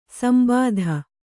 ♪ sambādha